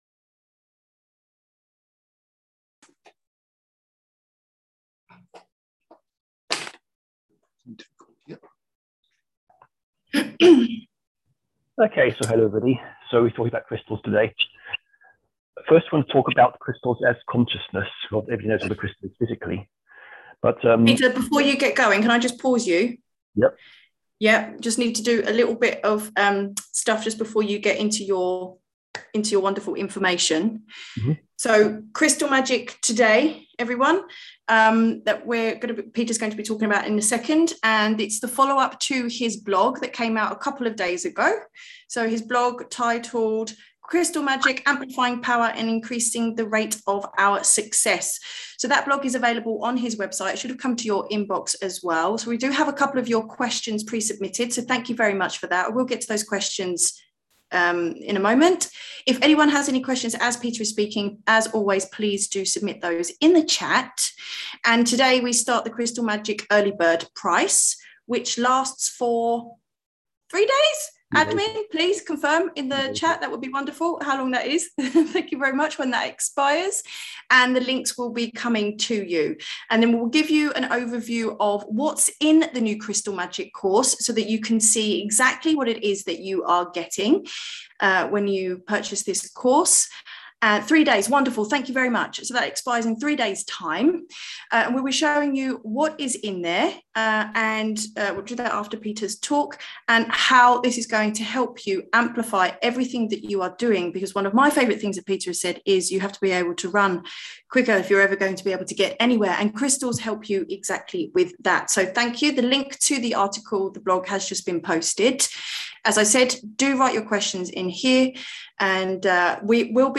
Call Replays